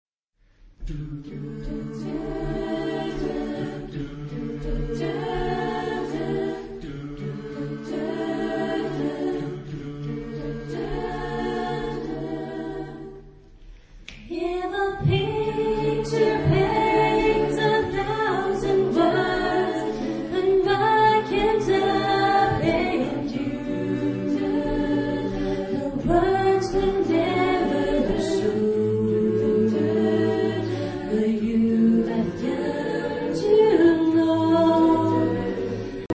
Type of Choir: SAATB  (5 mixed voices )
Soloist(s): Alt (1)  (1 soloist(s))
Tonality: D major
Discographic ref. : 7. Deutscher Chorwettbewerb 2006 Kiel
Consultable under : Jazz Vocal Acappella